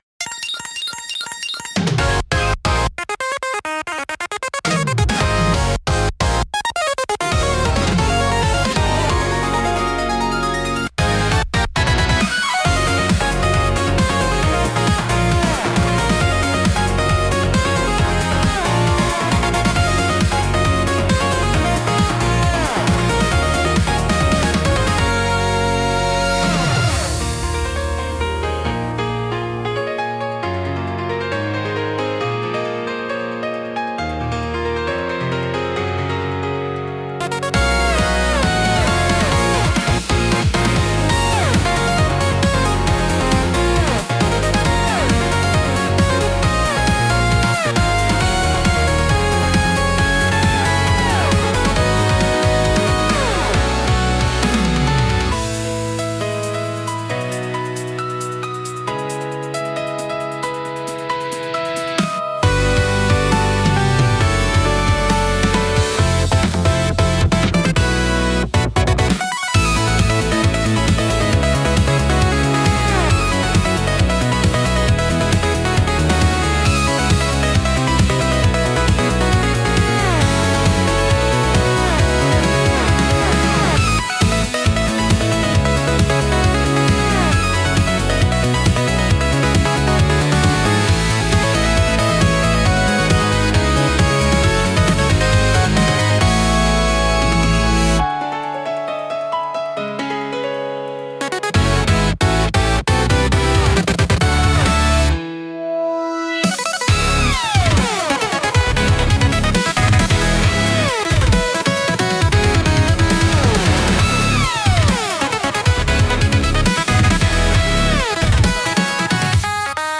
テンポの速いリズムと洗練されたサウンドで、スリルと爽快感を演出し、没入感を促進します。